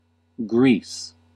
Ääntäminen
US : IPA : [ɡriːs]